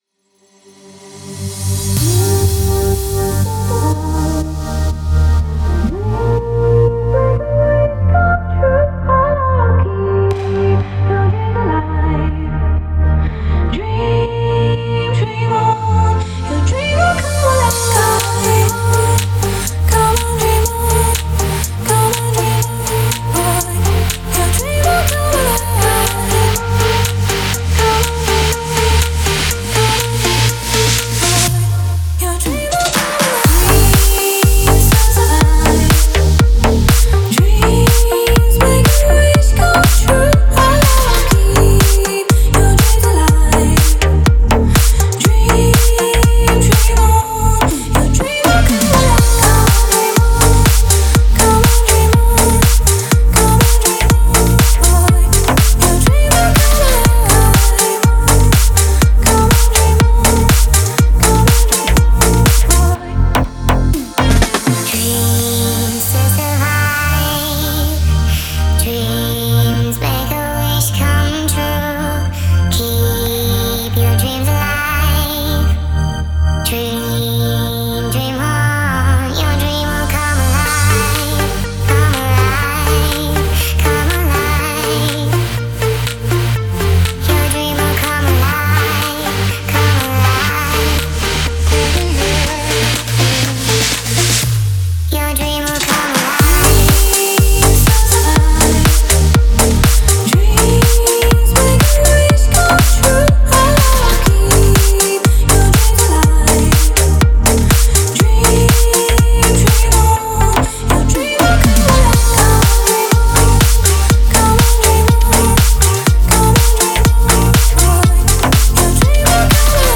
это энергичная композиция в жанре электронной музыки